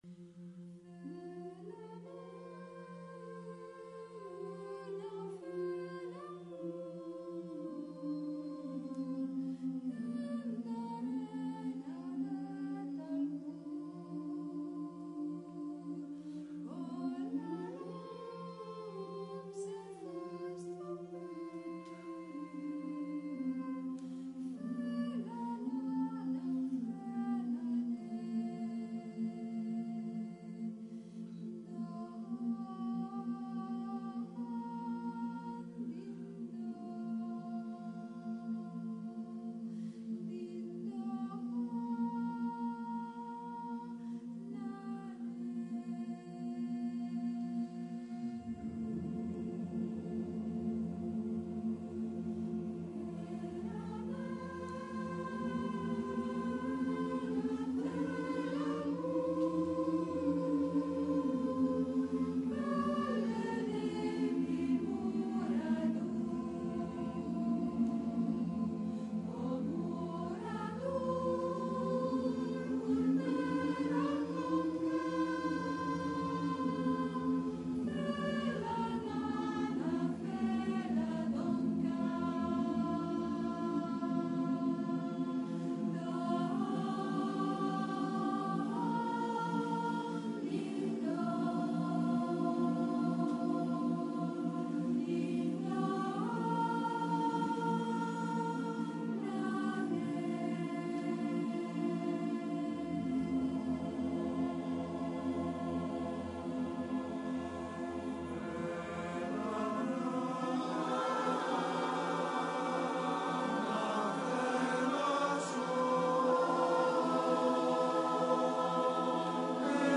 Ricerca, elaborazione, esecuzione di canti popolari emiliani